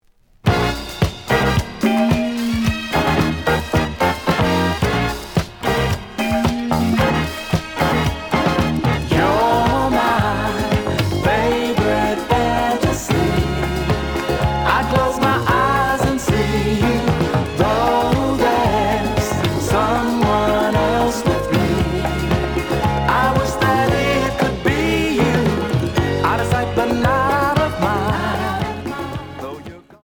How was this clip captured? The audio sample is recorded from the actual item. Slight edge warp.